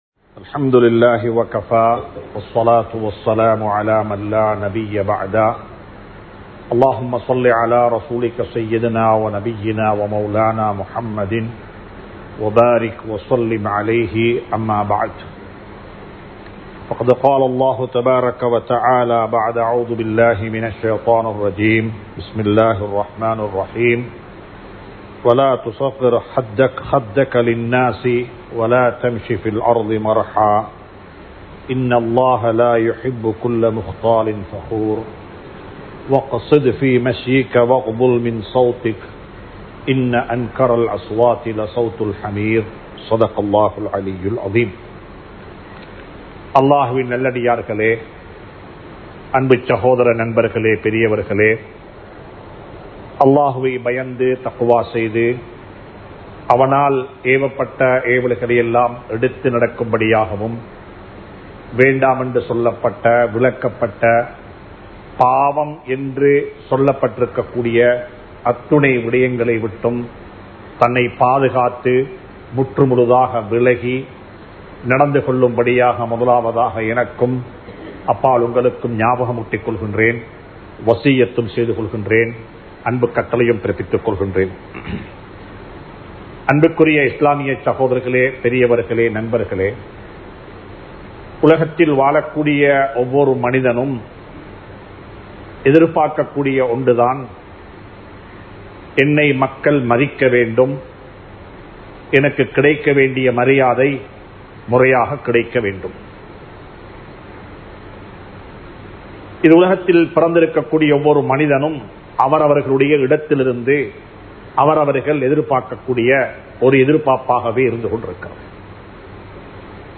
உறவுகளை மதியுங்கள் | Audio Bayans | All Ceylon Muslim Youth Community | Addalaichenai
Muhiyadeen Jumua Masjith